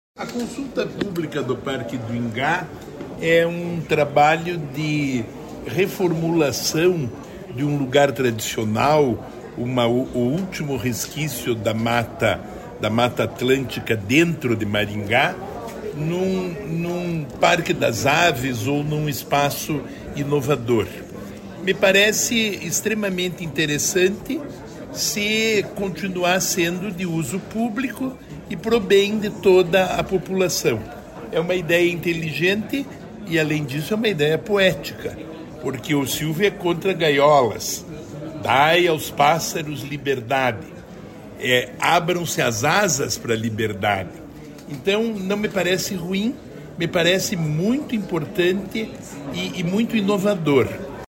O secretário de Estado do Desenvolvimento Sustentável, Rafael Greca, esteve no lançamento.